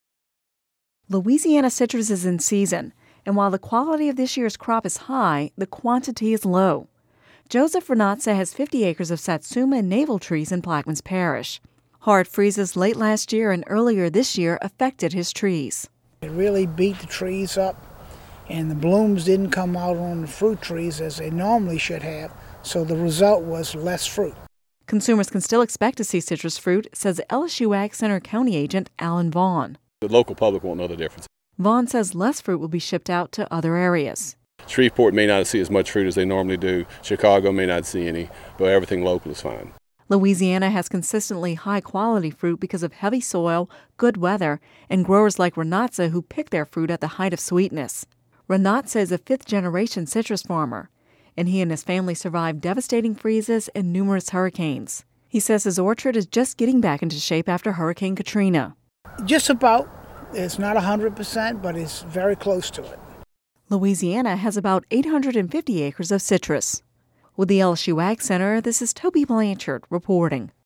(Radio News 12/20/10) Louisiana citrus is in season, and while the quality of the year’s crop is high, the quantity is low.